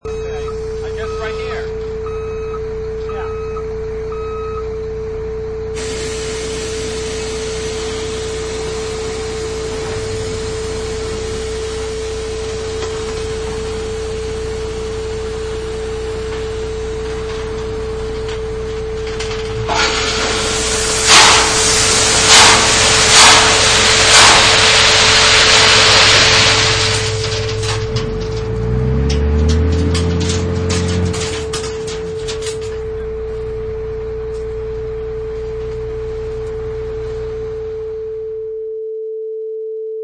Wav: Dump Truck Dumping 2
High quality audio of a dump truck dumping gravel
Product Info: 48k 24bit Stereo
Category: Vehicles / Trucks - Dumping
Relevant for: dumptrucks, engine, engines, diesel.
Try preview above (pink tone added for copyright).
Dump_Truck_Dumping_2.mp3